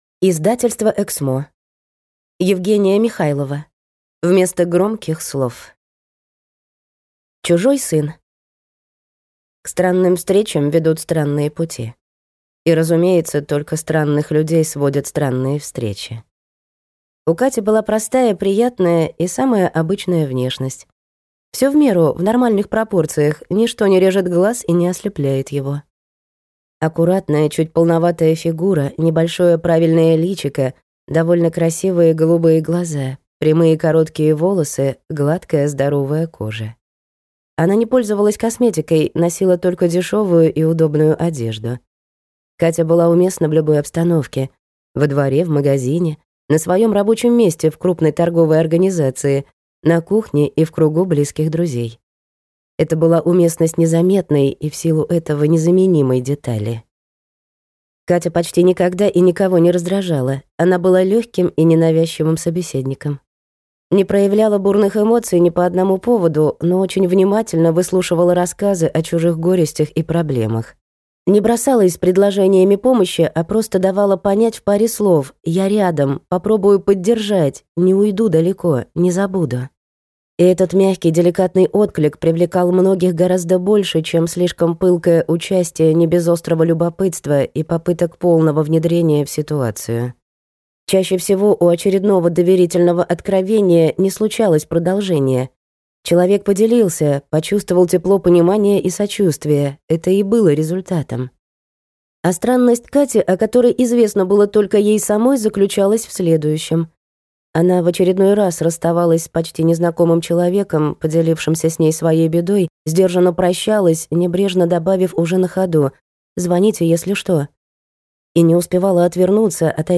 Аудиокнига Вместо громких слов | Библиотека аудиокниг